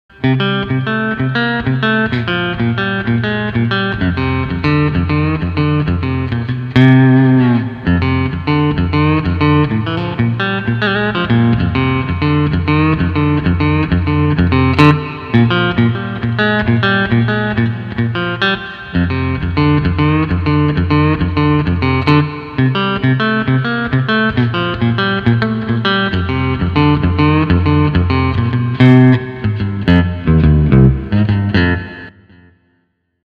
MSA Legend played through a Fender Steel King!
All samples played on an MSA Legend plugged into a Boss RV-5 (Hall Reverb) into a Fender Steel King amp placed about a foot off my living room carpet. Recorded into a Shure SM-57 pointed on axis with the center of the speaker cone and 3" from the speaker grill.
These sound samples sound identical to what I am hearing at my steel so the recording process seems to be faithfull to the original sound including room accoustics.
C6th9HonkyTonk.wma